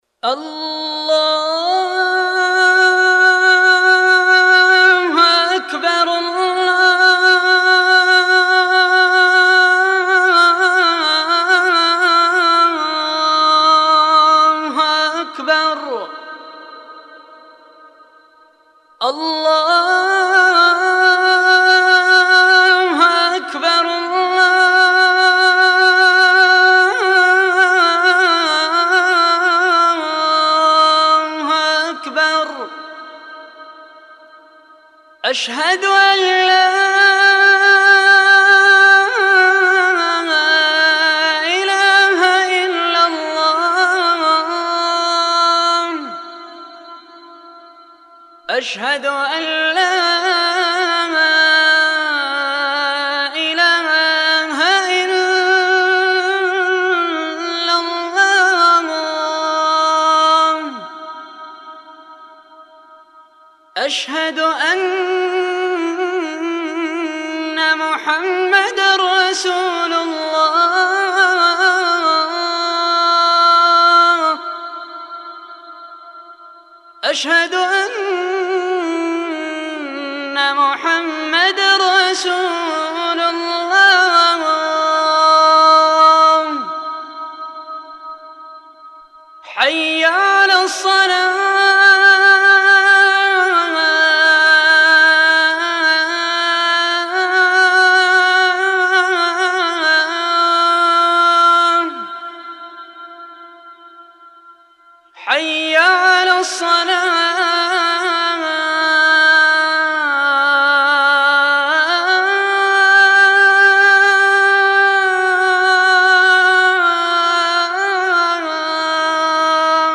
المكتبة الصوتية روائع الآذان المادة آذان